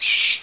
1 channel
bat.mp3